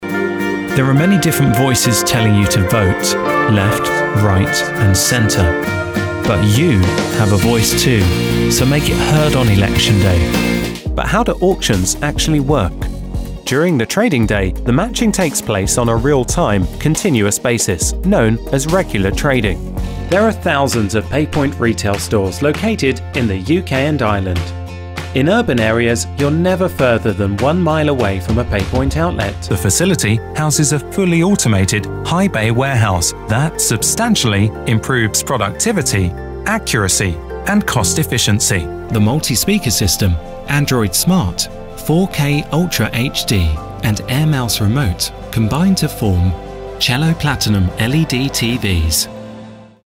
Male
English (British)
A neutral accent, British male, mid-toned voice with a natural warm, friendly and professional sound.
Also known for being clear, conversational and upbeat.
Corporate
Friendly, Warm, Professional.